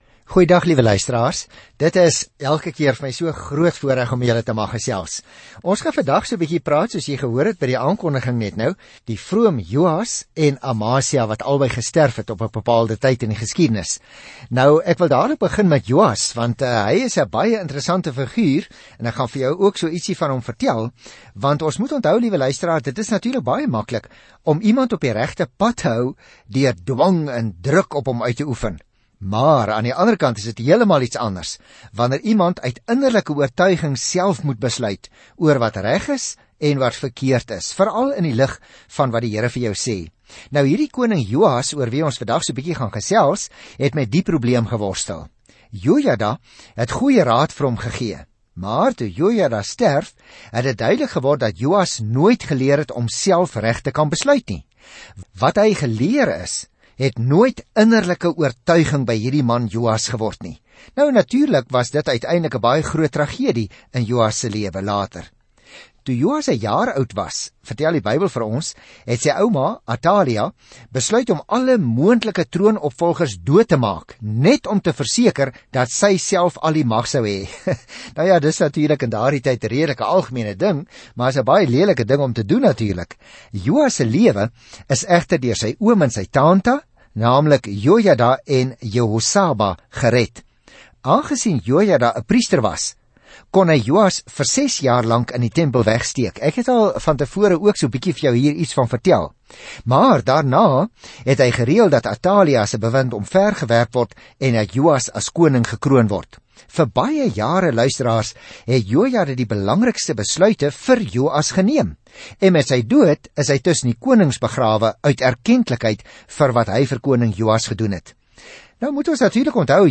Skrif 2 KRONIEKE 24 2 KRONIEKE 25 Dag 12 Begin met hierdie leesplan Dag 14 Aangaande hierdie leesplan In 2 Kronieke kry ons 'n ander perspektief op stories wat ons oor Israel se vorige konings en profete gehoor het. Reis daagliks deur 2 Kronieke terwyl jy na die oudiostudie luister en uitgesoekte verse uit God se woord lees.